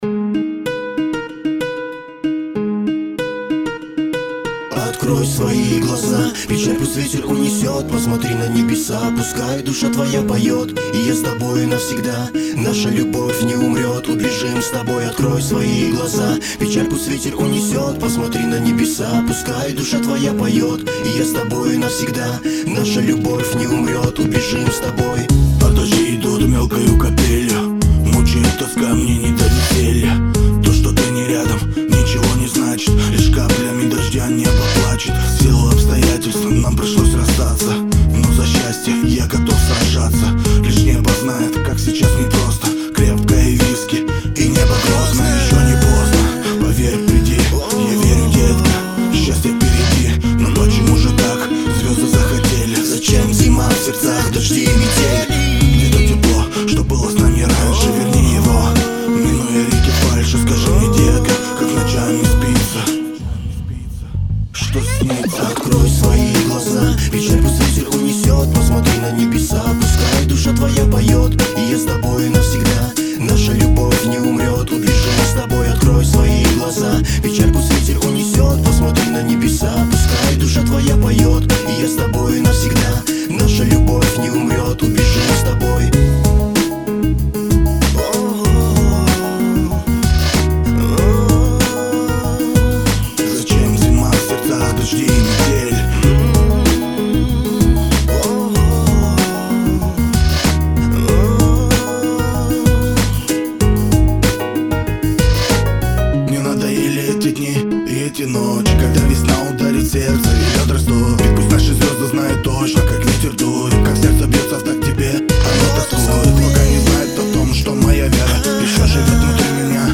Категория: Рэп, хип - хоп